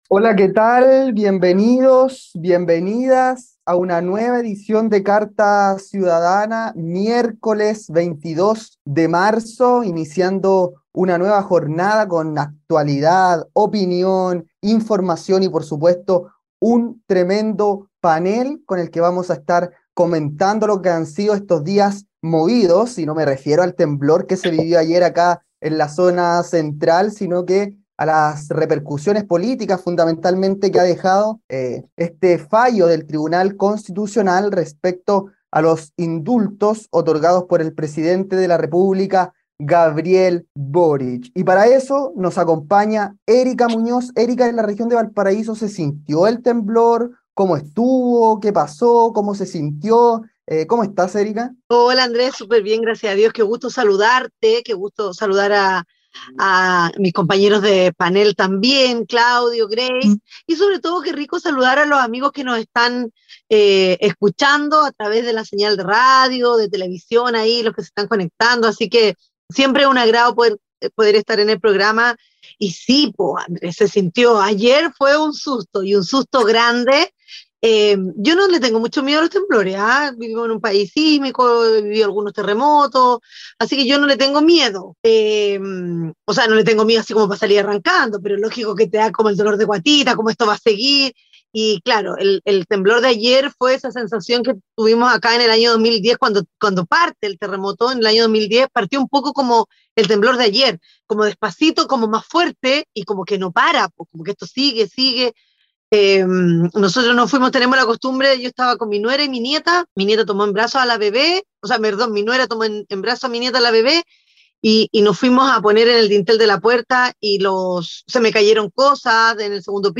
🎙 Carta Ciudadana es un programa radial de conversación y análisis sobre la actualidad nacional e internacional, y emitido desde 2020 en más de 40 radios a lo largo de todo Chile.